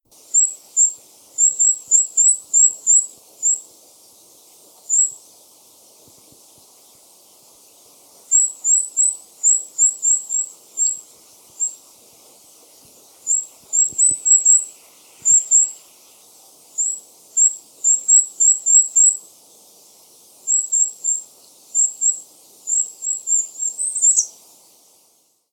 goudhaan
♪ contactroep
goudhaantje_roep.mp3